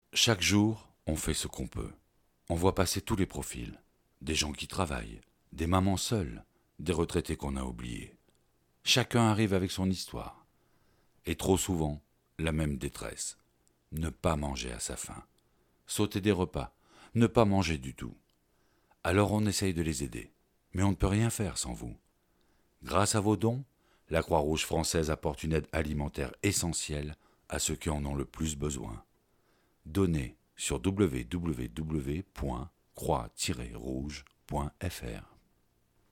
Voix off Croix-Rouge
Comédien voix
40 - 60 ans - Baryton-basse